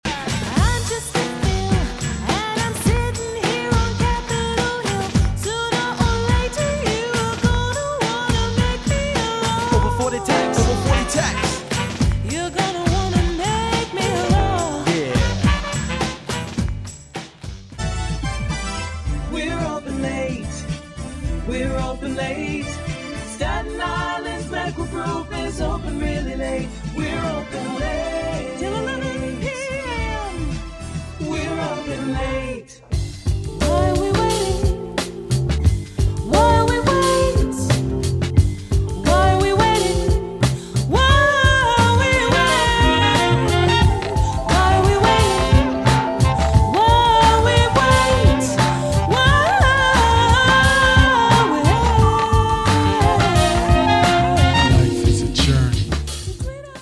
Young Adult, Adult, Mature Adult
standard us | natural
singer